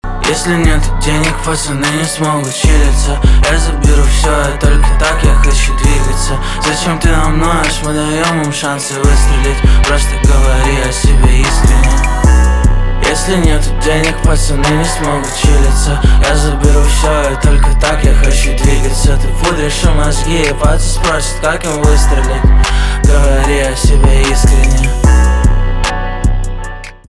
русский рэп , битовые , басы
пианино